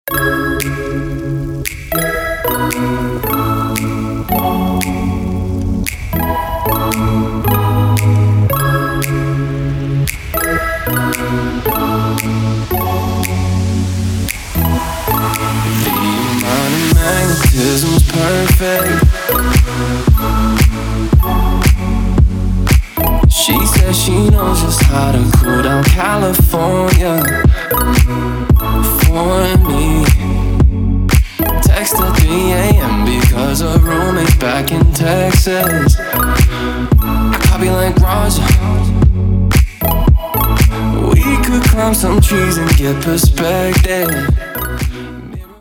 dance
Electronic
колокольчики
звонкие
ксилофон